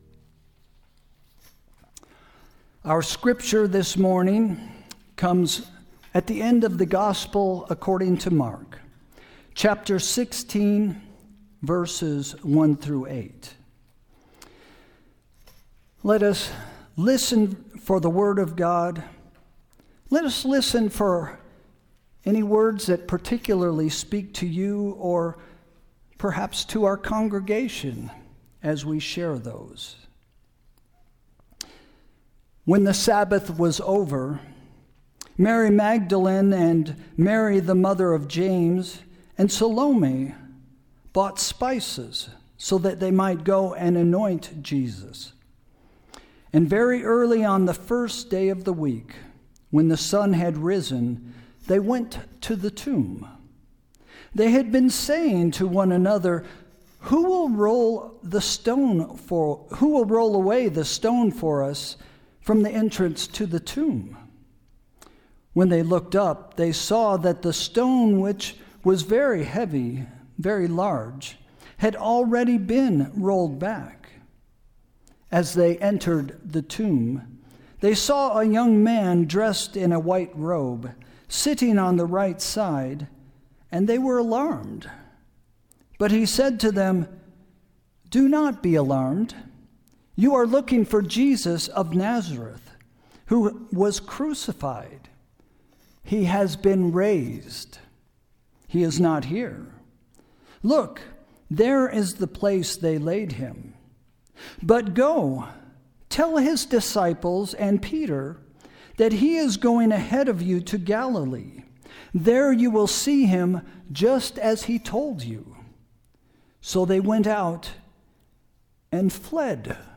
Sermon – Easter Sunday, April 5, 2026 – “Too Good To Be True” – First Christian Church